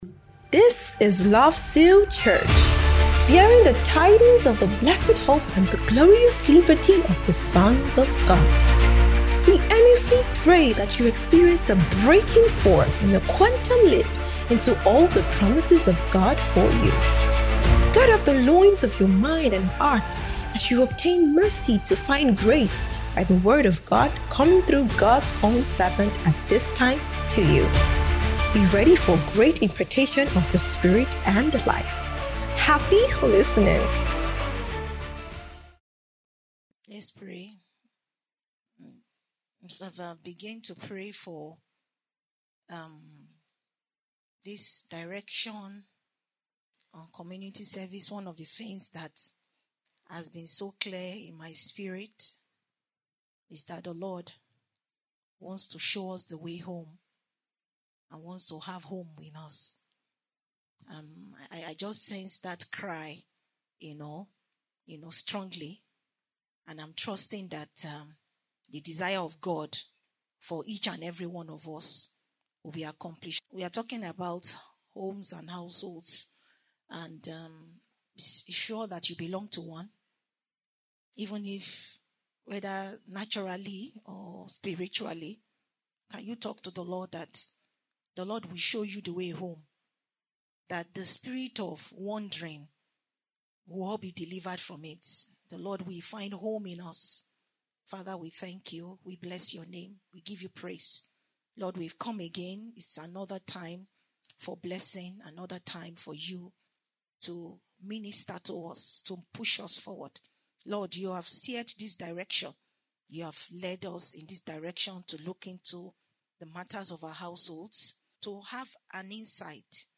Kingdom Believers’ Community Service